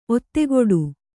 ♪ ottegoḍu